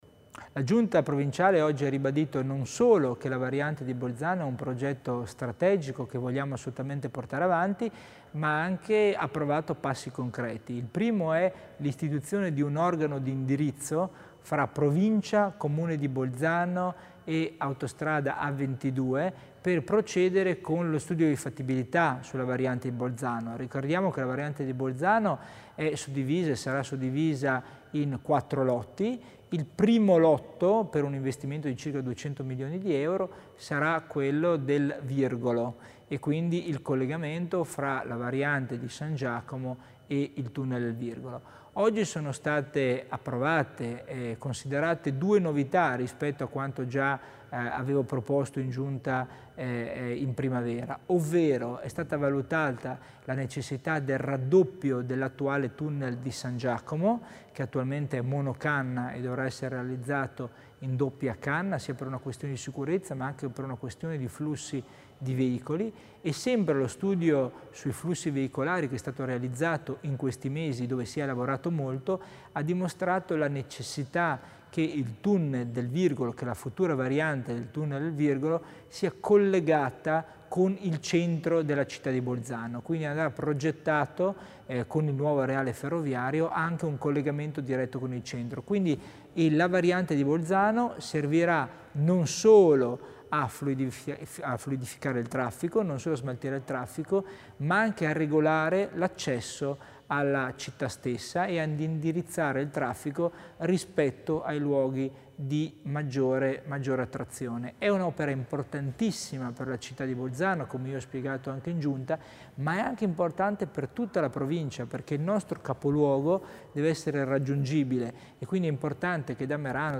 L'Assessore Tommasini illustra il progetto della circonvallazione di Bolzano